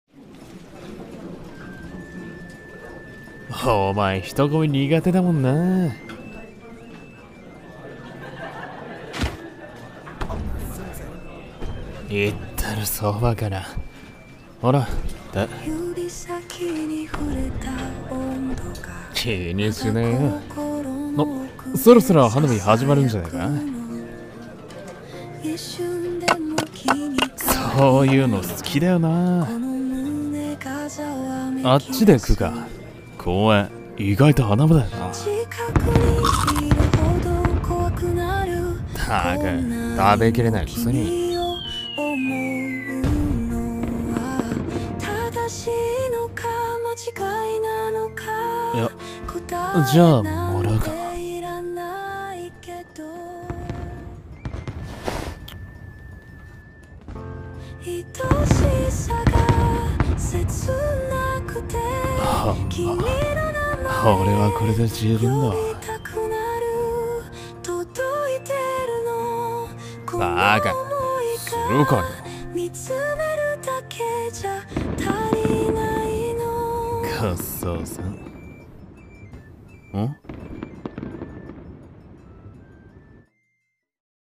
それはリンゴ飴の味 【2人声劇】